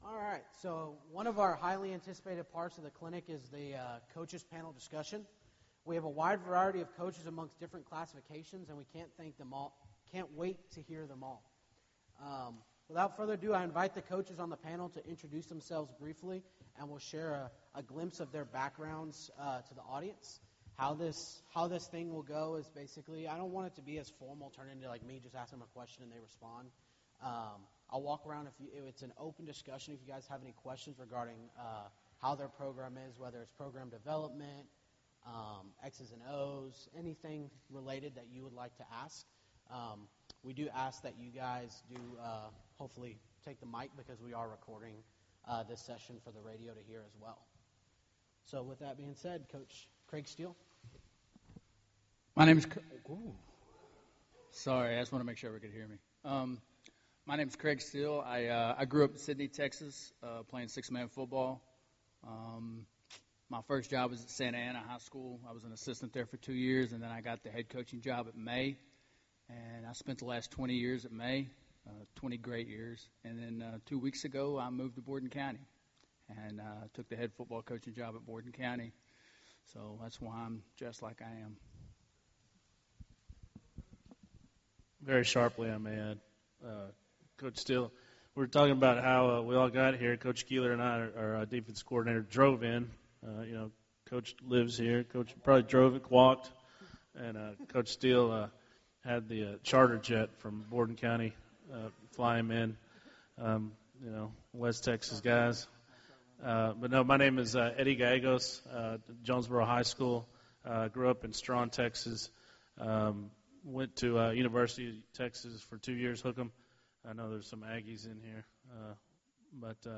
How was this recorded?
I was honored to be able to speak on a coaches panel at this first (hope to be annual) "The Six" sixman coaching clinic.